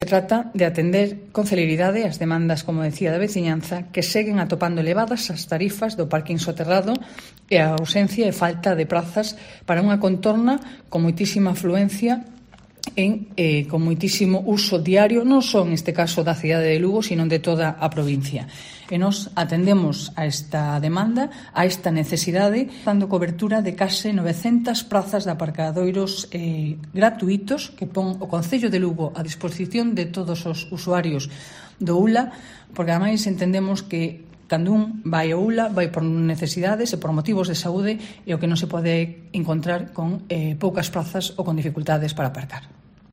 La alcaldesa de Lugo, Lara Méndez, anuncia la creación de 340 plazas de aparcamiento cerca del HULA